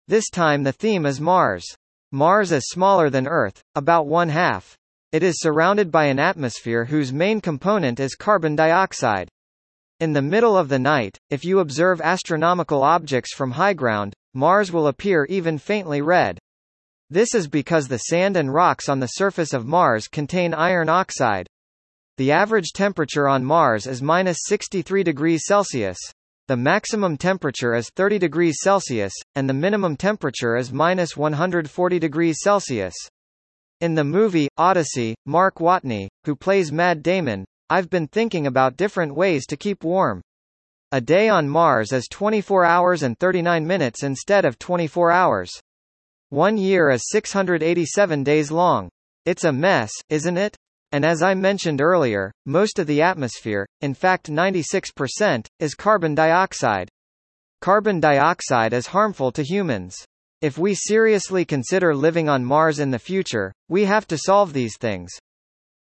本文読み上げ